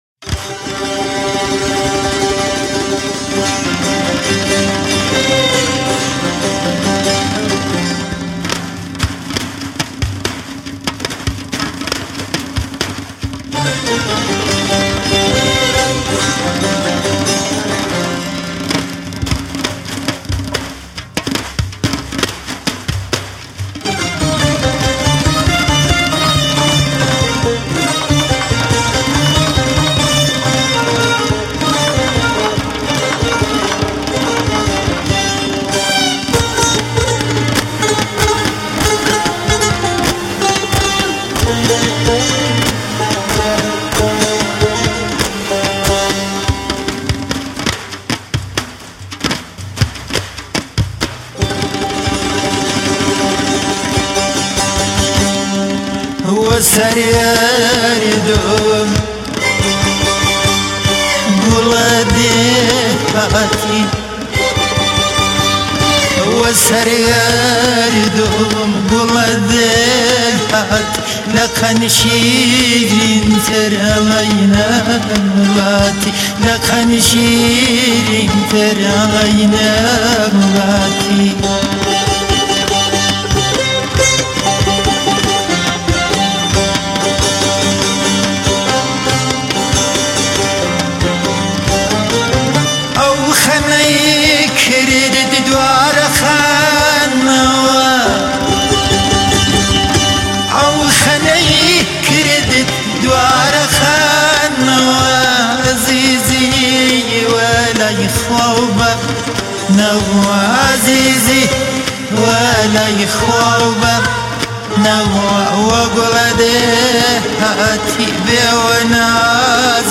جدیدترین اهنگ های کردی غمگین